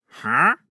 Minecraft Version Minecraft Version 1.21.5 Latest Release | Latest Snapshot 1.21.5 / assets / minecraft / sounds / mob / wandering_trader / idle2.ogg Compare With Compare With Latest Release | Latest Snapshot